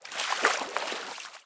water-06.ogg